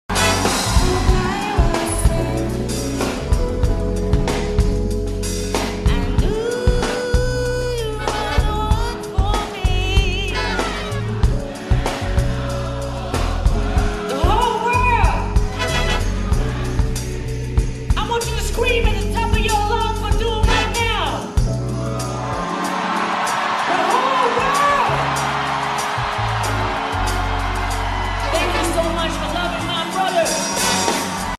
soulful rendition